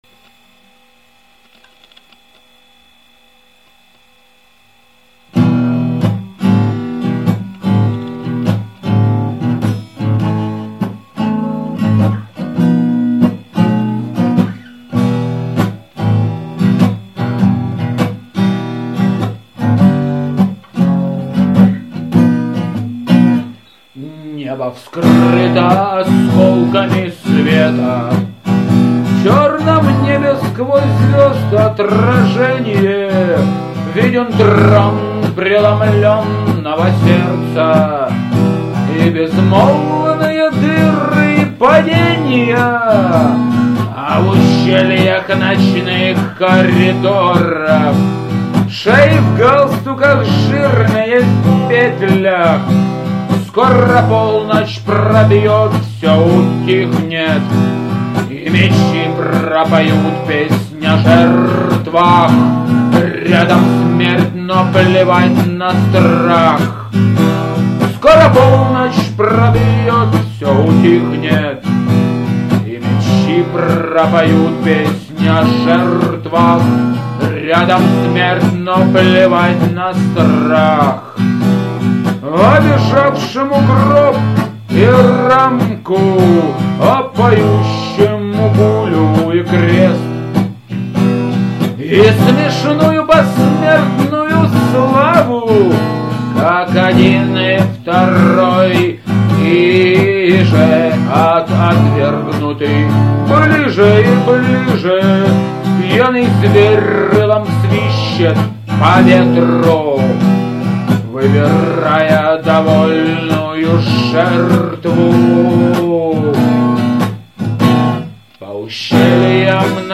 Mp3 Небо Меч Песнь Звёзды Сердце Альтернативный рок